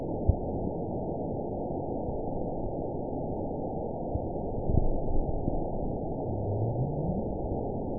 event 920448 date 03/26/24 time 01:03:19 GMT (1 month ago) score 9.55 location TSS-AB05 detected by nrw target species NRW annotations +NRW Spectrogram: Frequency (kHz) vs. Time (s) audio not available .wav